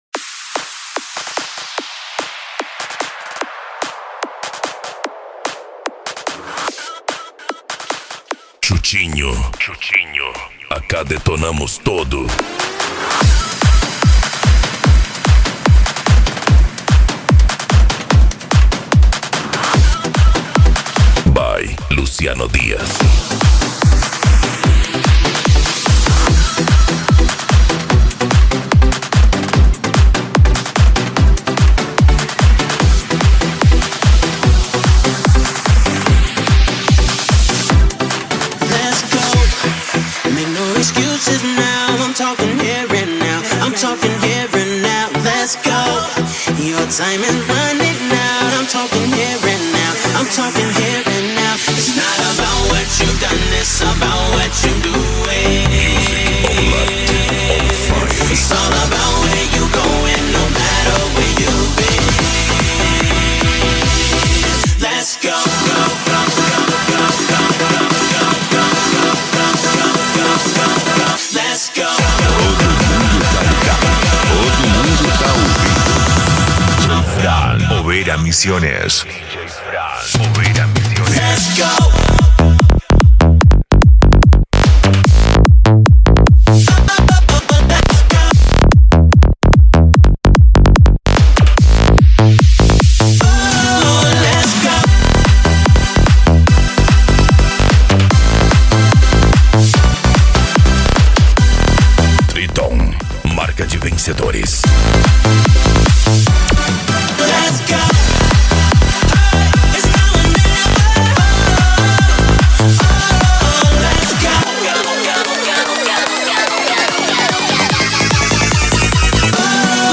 Psy Trance